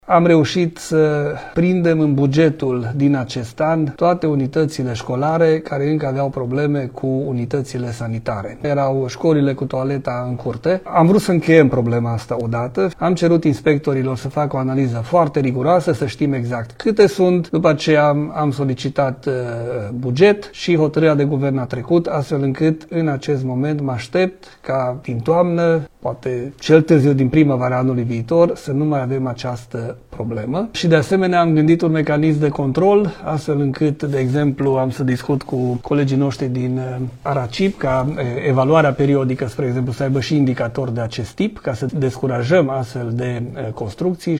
Ministrul Educației, Daniel David, spune că speră ca această problemă să fie rezolvată până în toamnă sau cel târziu până la primăvară. Ministrul a venit cu aceste precizări după ce Guvernul a aprobat alocarea a 14 milioane și jumătate de lei pentru modernizarea celor 175 de școli care au toalete în curtea instituției de învățământ.